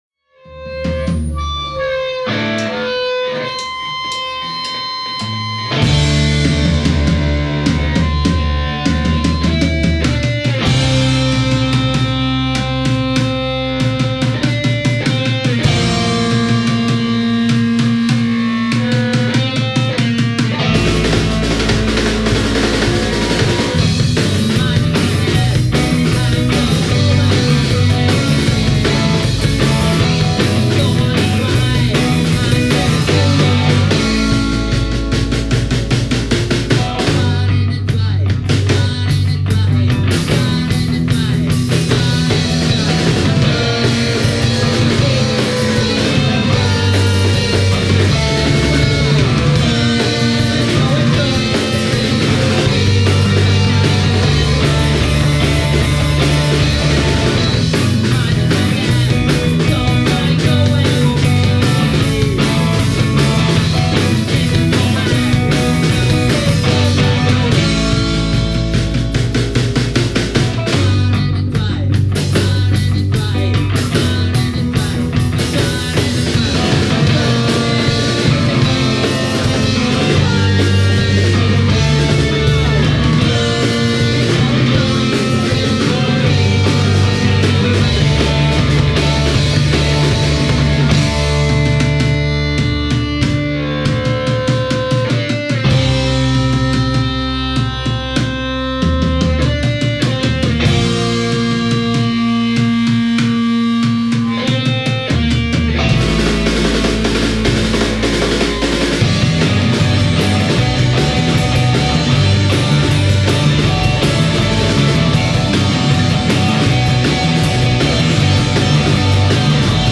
先日の練習から。
@ Studio Rat, Dec.19 2009